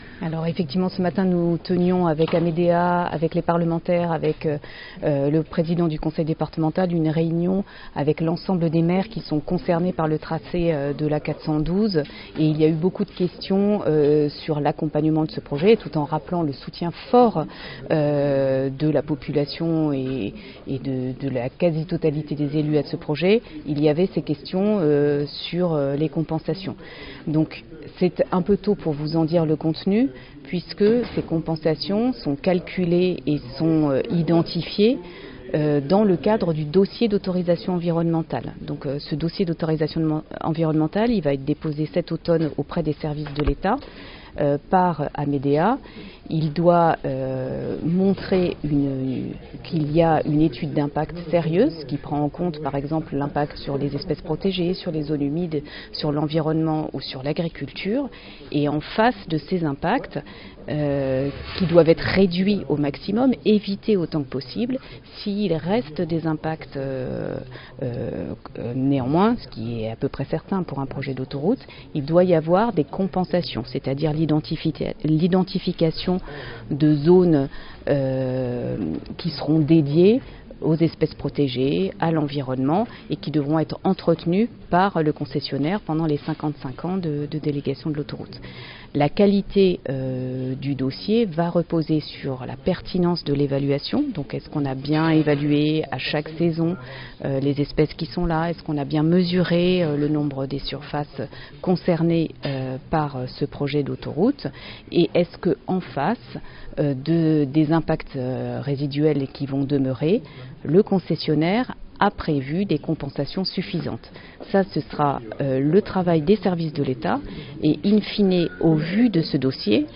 Près de 80% des habitants du Chablais favorables à l'autoroute A412 (interview)